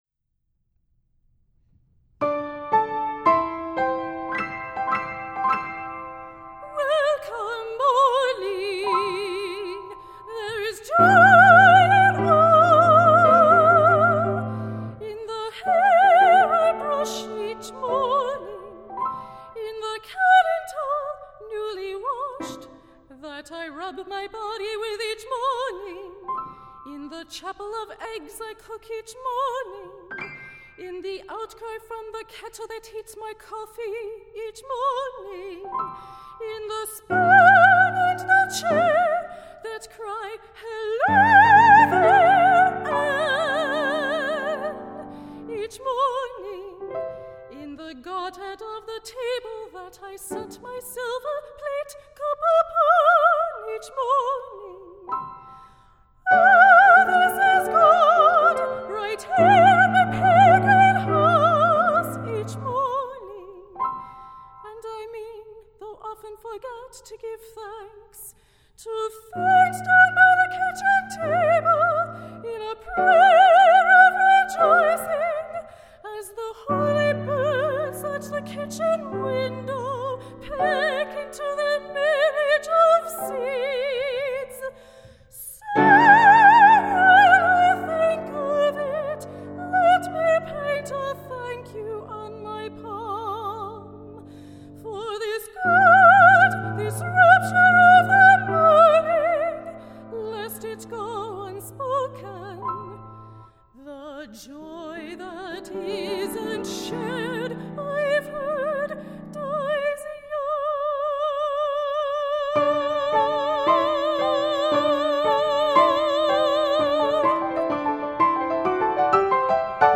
for High Voice and Piano (2013)